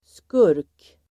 Uttal: [skur:k]